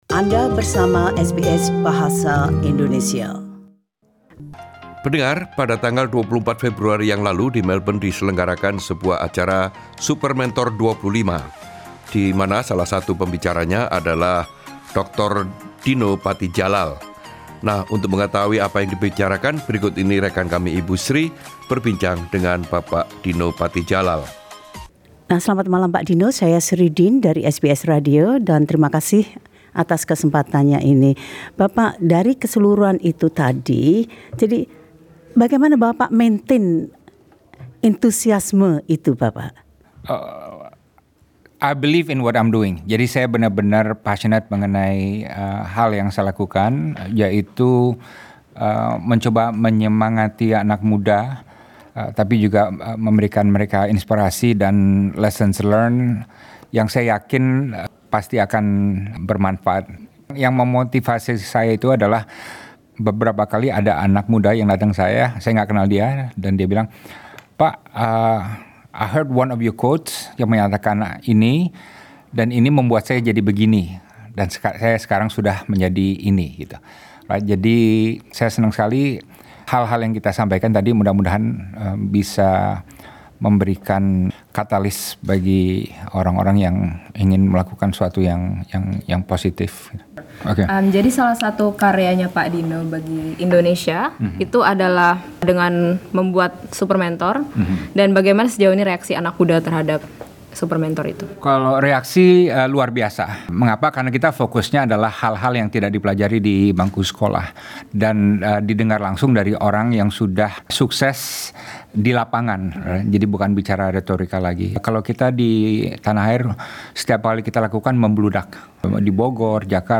Dr Dino Patti Djalal, mantan Duta Besar Indonesia untuk Amerika Serikat dan pemimpin program Supermentor berbicara tentang upayanya untuk menginspirasi orang Indonesia di dalam dan di luar negeri untuk menjadi yang terbaik yang mereka dapat, sebagai individu dan sebagai bangsa.